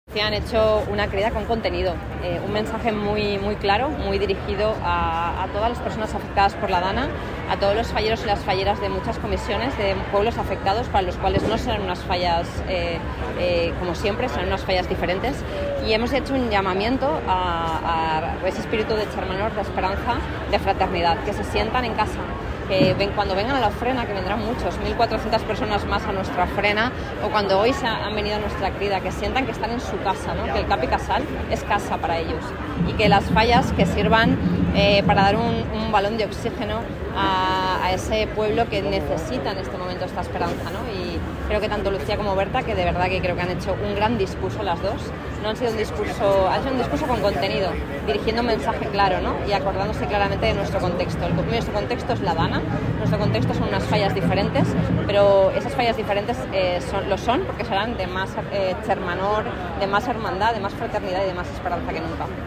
El pregón de la Crida ha proclamado la vocación de superación de las valencianas y los valencianos. Las Torres de Serranos han sido el escenario, como es tradicional, de un gran espectáculo multidisciplinar que sirve de invitación a participar en las celebraciones.